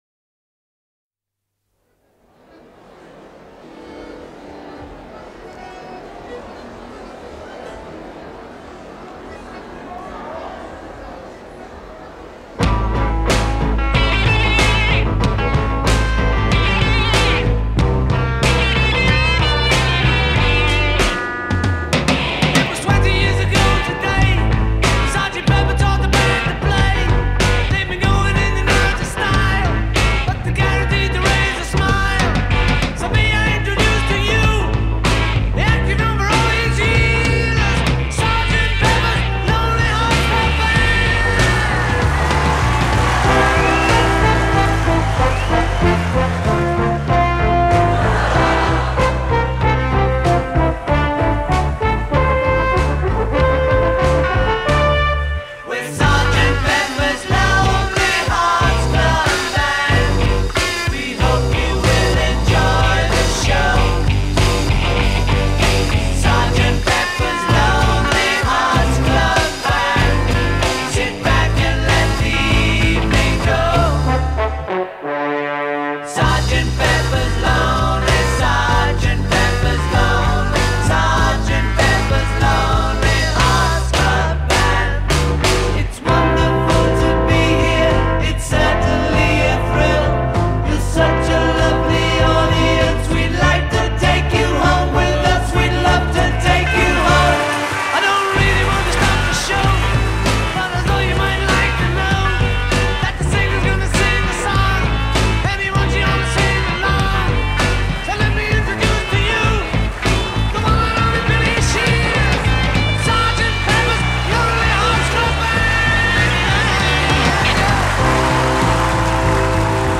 Rock, Psychedelic Rock